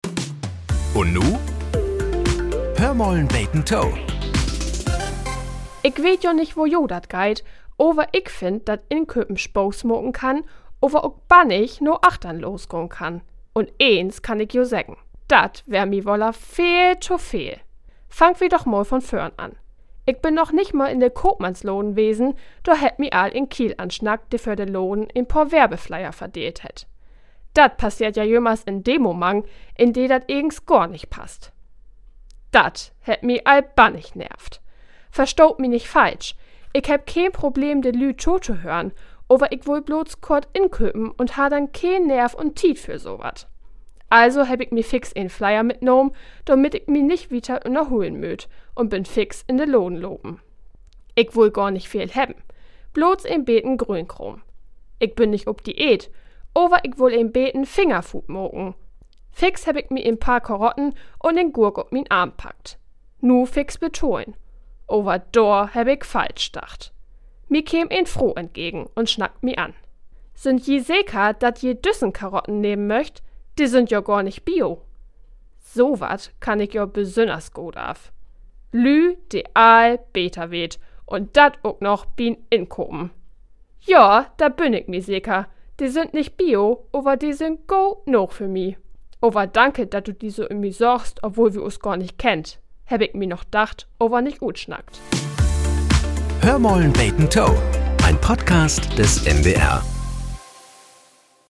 Nachrichten - 21.01.2025